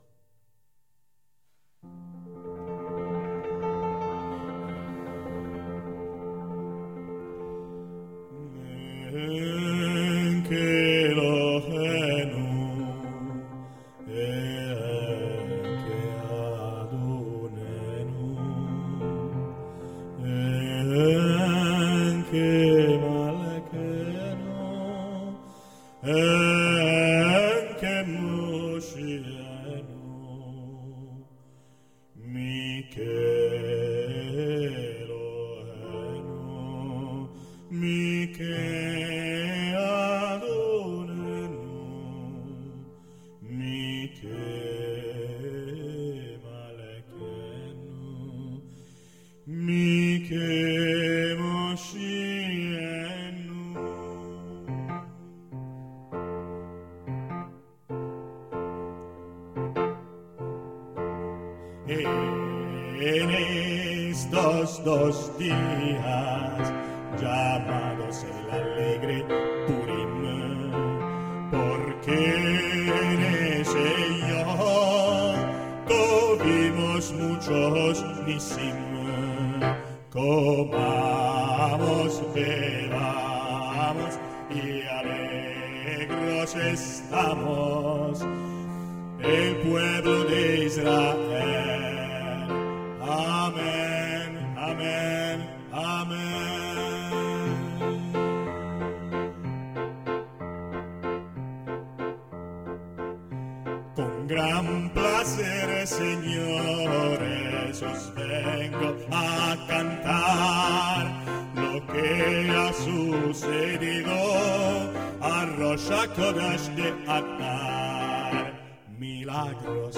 En kelohenu - En estos dos dias - Con gran plaser segnores canto sinagogale in ebraico e canti di Purim in giudeospagnolo
piano
registrazione effettuata al Teatro Paisiello di Lecce il 27 gennaio 2004